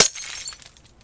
glass_shatter.1.ogg